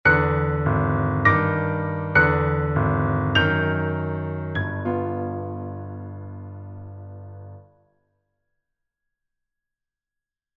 • 人声数拍
• 大师演奏范例
我们是钢琴练习教材专家